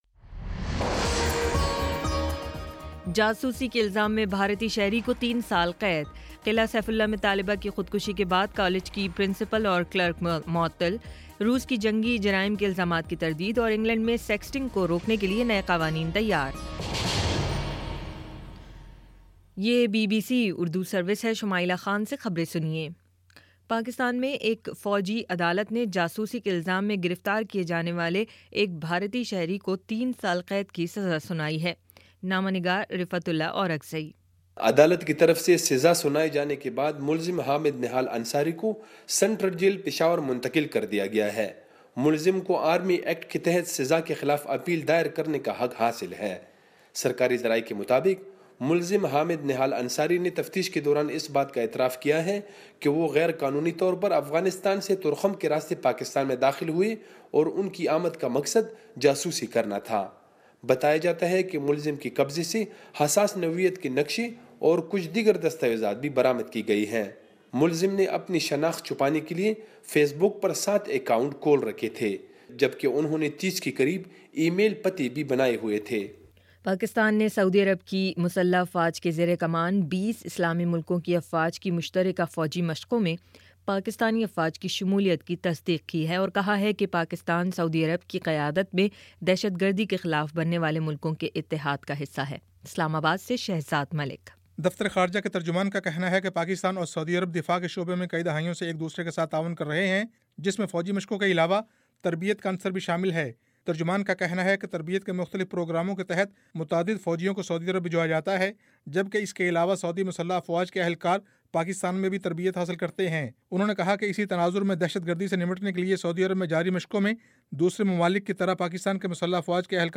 فروری 16 : شام چھ بجے کا نیوز بُلیٹن